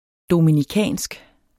Udtale [ dominiˈkæˀnsg ]